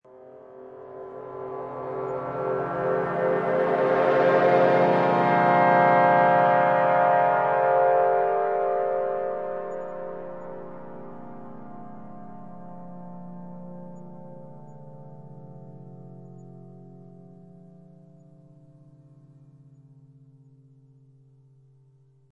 Download Trombone sound effect for free.
Trombone